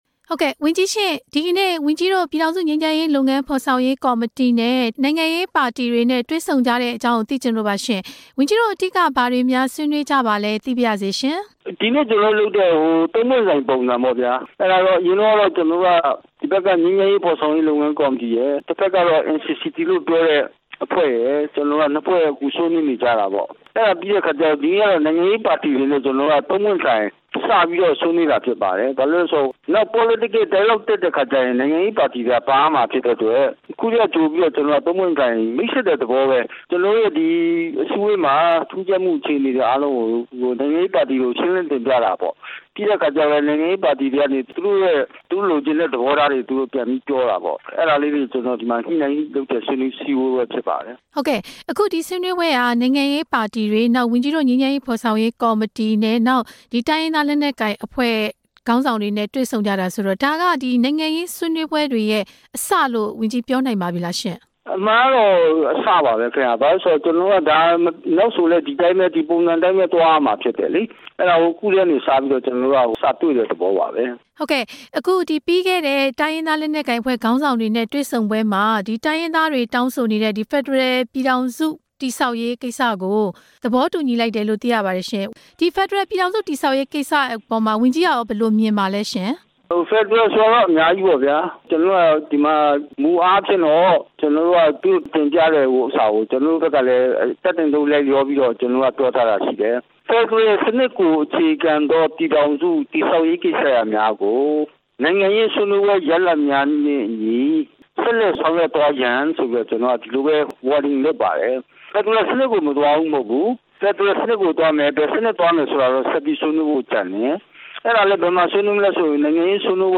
ငြိမ်းချမ်းရေး လုပ်ငန်းစဉ်တွေအကြောင်း ဝန်ကြီး ဦးအောင်မင်းနဲ့ မေးမြန်းချက်